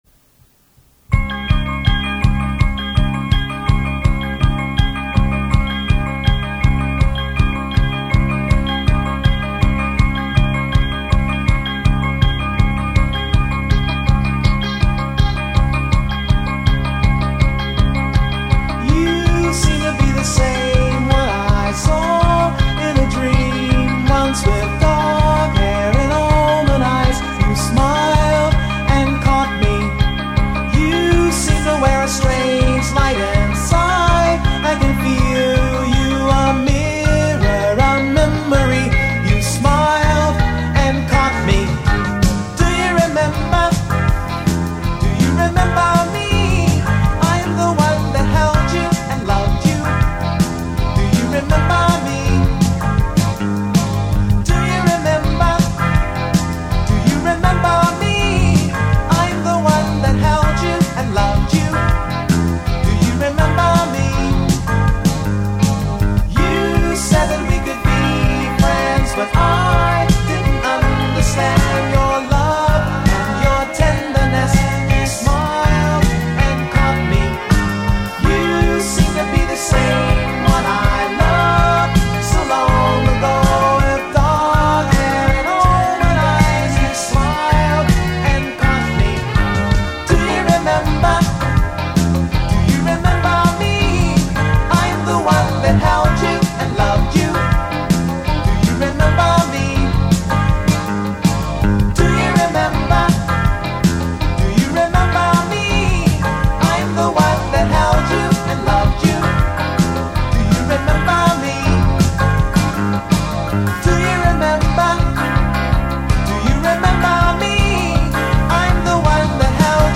lead vocals
background vocals
guitar
Drums